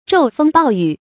驟風暴雨 注音： ㄓㄡˋ ㄈㄥ ㄅㄠˋ ㄧㄩˇ 讀音讀法： 意思解釋： 見「驟雨暴風」。